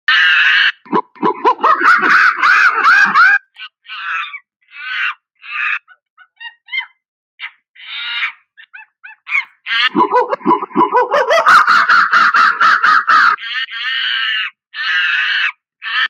monke noise